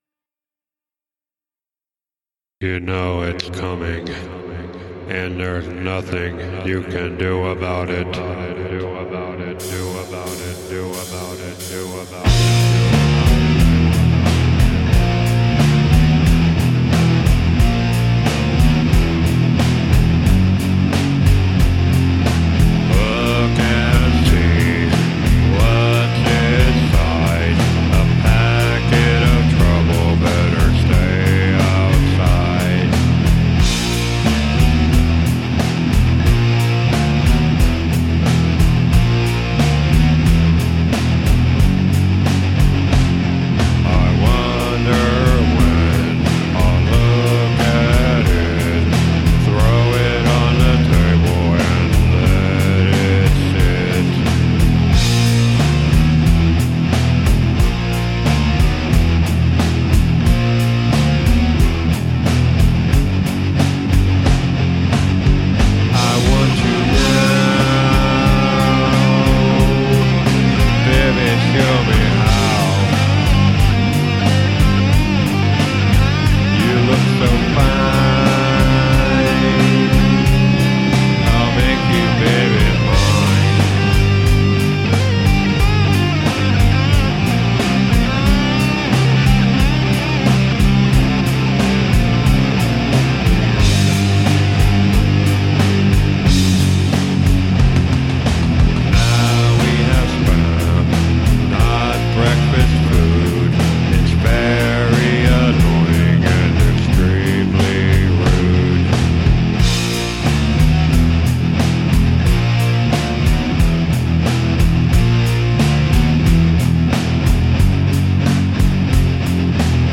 Here is a song I wrote about junk mail. It's an older recording and my mixing skill were not to good at the time. It could use a better vocalist and lead but I did the best I could. It's a simple song and I tried to give it an sort of evil sound.